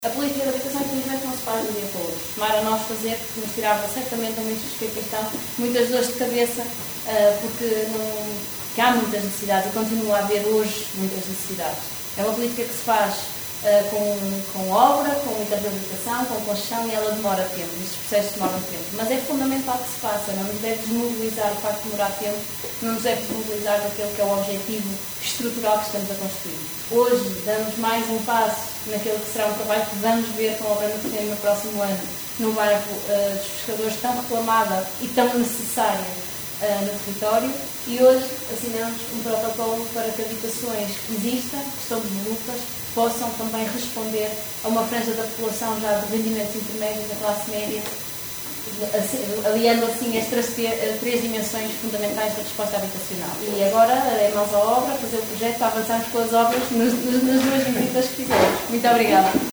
Terminadas as visitas, seguiu-se uma sessão protocolar na Biblioteca Municipal de Caminha, onde as várias entidades procederam às assinaturas dos contratos.
Ministra da Habitação, hoje em Caminha, a presidir a assinatura de protocolos no âmbito do Programa 1º Direito.